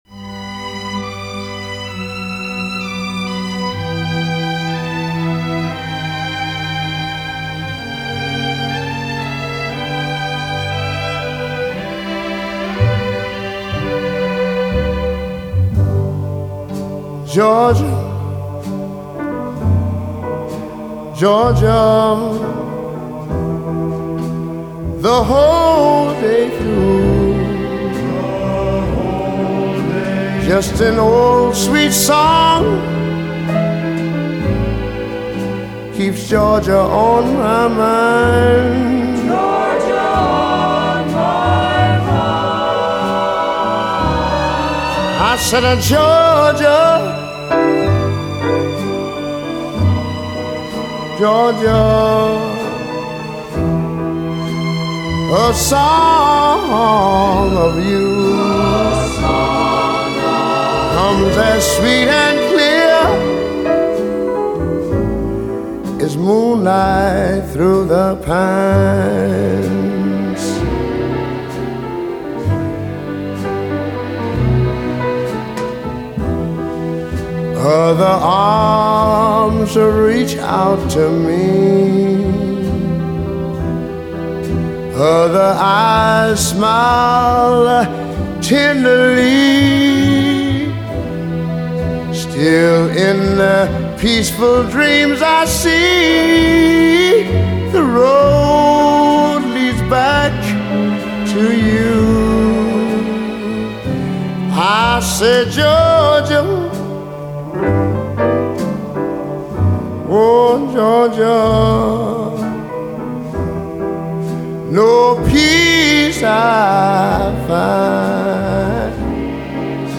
И немного для вечернего настроения джазовой музыки.